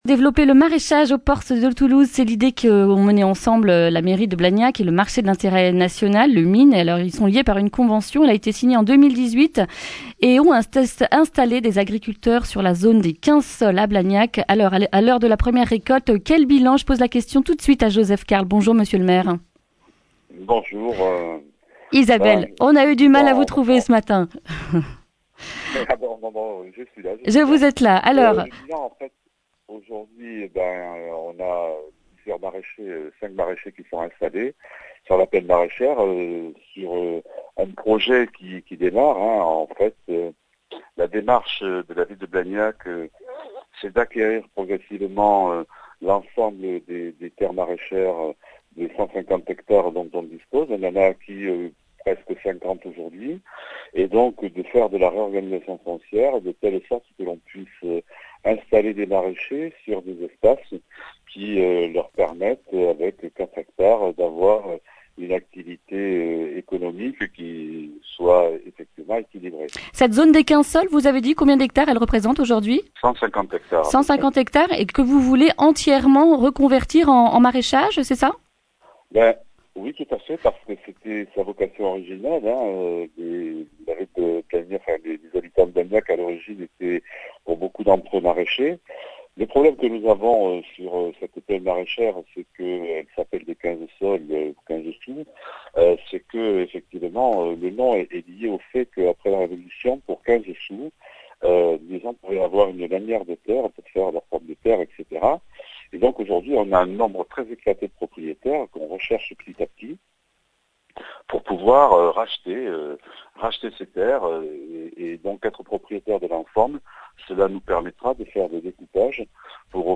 jeudi 27 juin 2019 Le grand entretien Durée 10 min
Objectif : acquérir l’ensemble des terres de la zone dite des « 15 sols » et y installer des maraichers en leur garantissant l’écoulement de leur production au MIN. Une démarche environnementale, que nous explique Joseph Carles, maire de Blagnac.